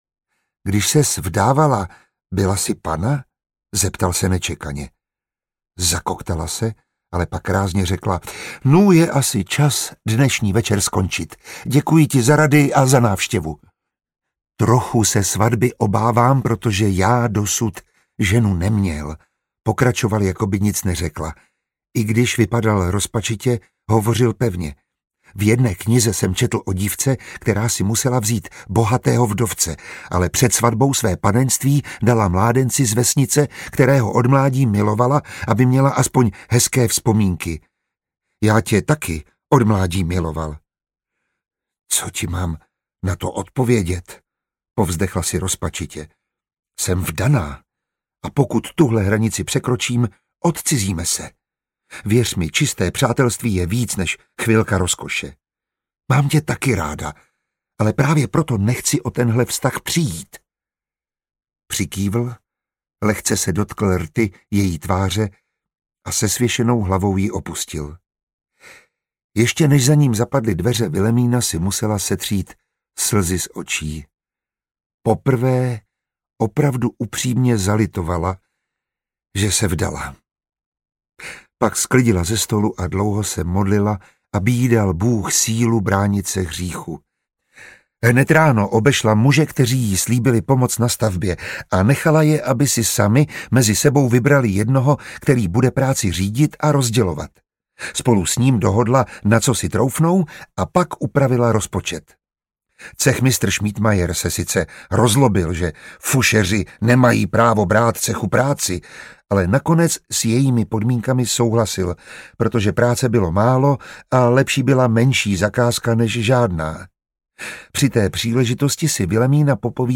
Křišťálový klíč III. audiokniha
Ukázka z knihy
• InterpretMiroslav Táborský, Eva Josefíková